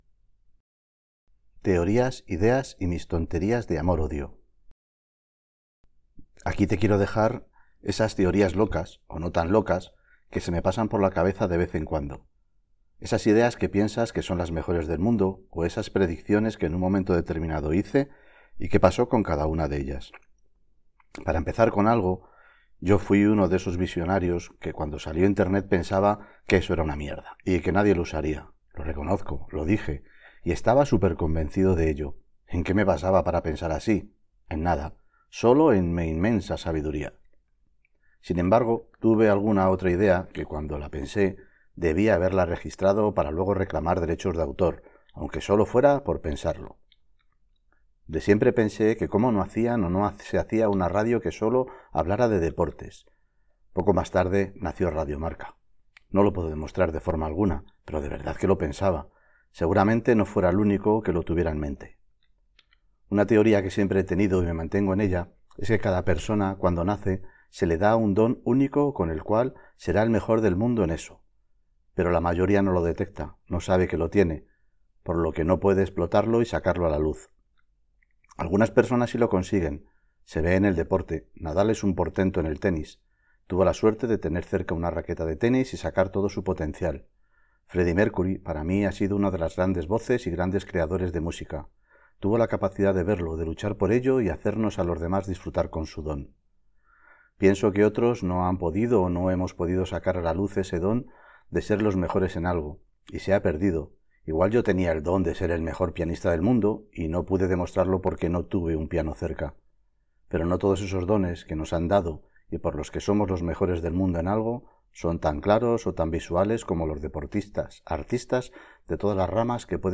Audiolibro - Todo va a salir bien - TEORIAS, IDEAS y MIS TONTERIAS DE AMOR-ODIO - Asociación Esclerosis Múltiple